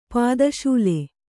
♪ pāda śule